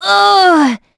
Hilda-Vox_Damage_03.wav